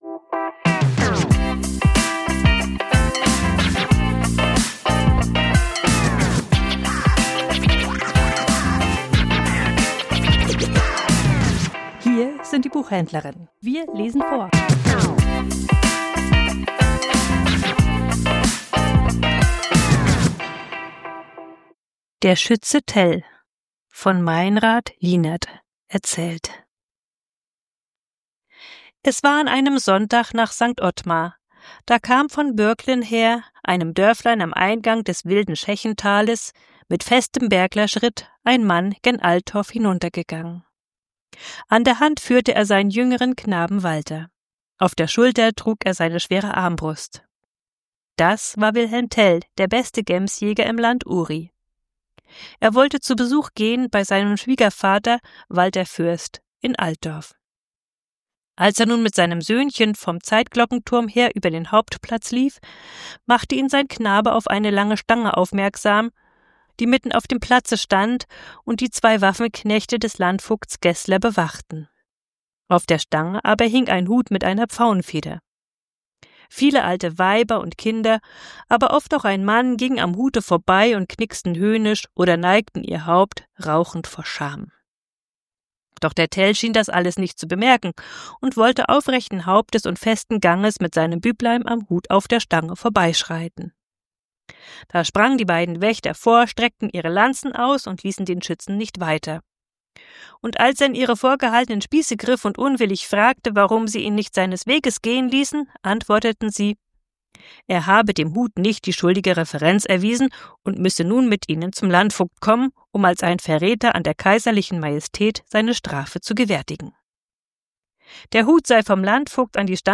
Vorgelesen: Der Schütze Tell ~ Die Buchhändlerinnen Podcast